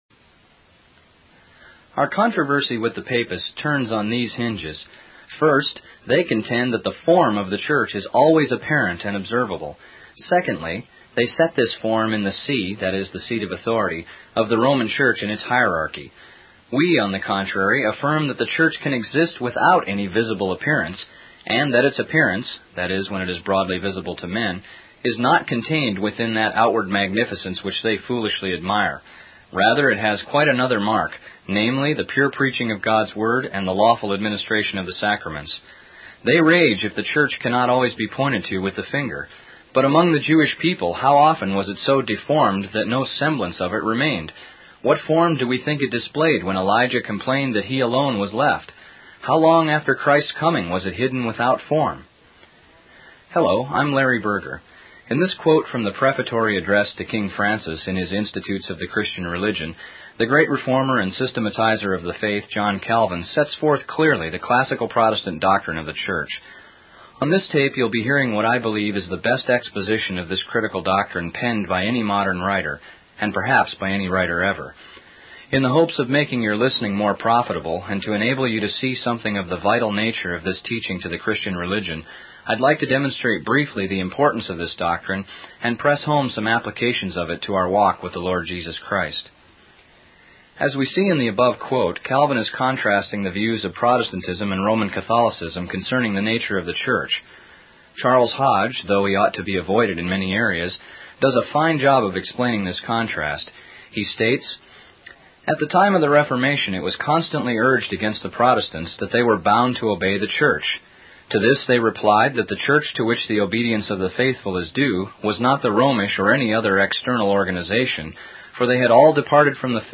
Debate on the Meaning of the Church in Reformation Thought